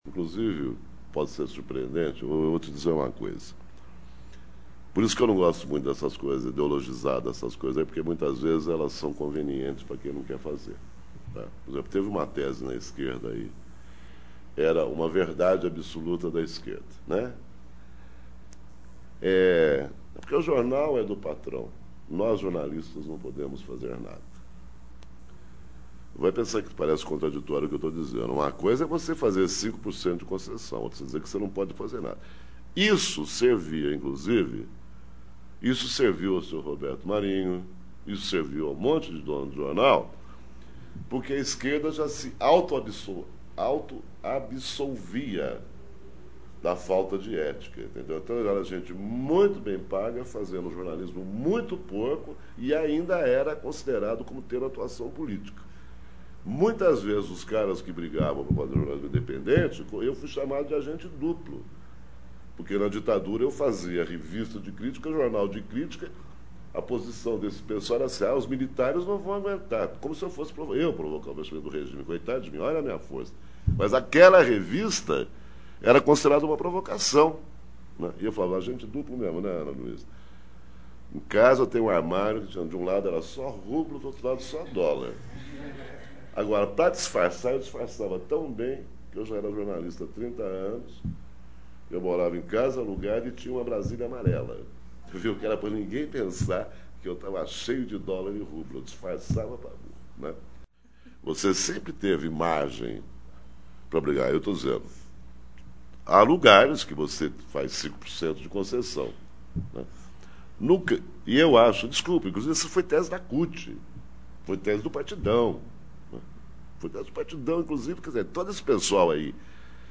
Também falou do rombo das privatizações, os dilemas das esquerdas e o posicionamento político dos jornais na era FHC. Abaixo, ouça trecho do áudio da palestra.